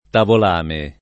[ tavol # me ]